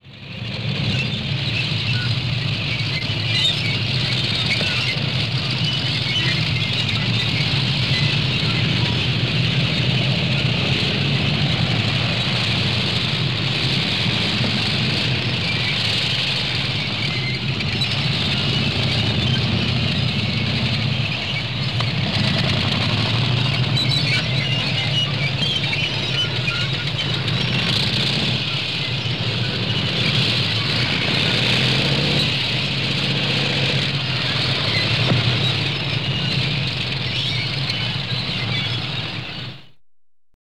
Звуки движения танка, скрипа гусениц
Звук движения танка